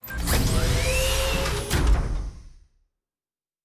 Sci-Fi Sounds / Doors and Portals / Door 4 Open.wav
Door 4 Open.wav